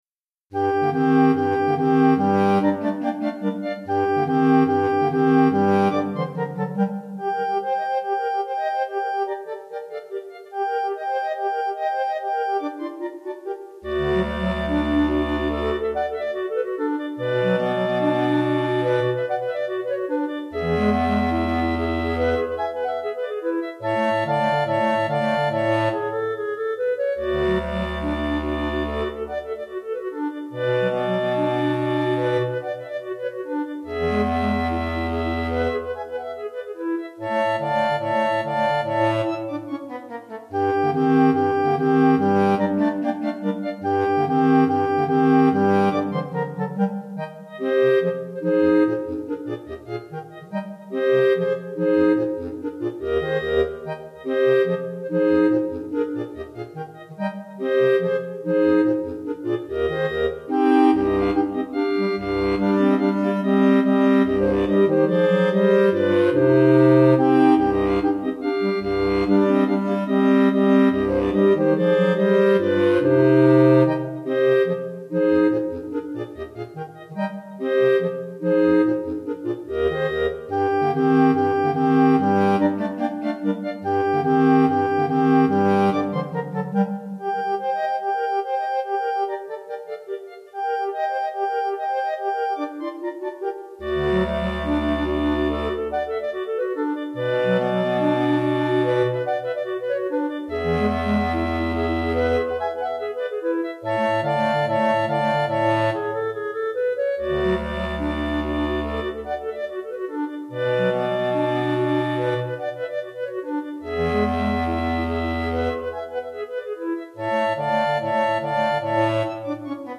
4 Clarinettes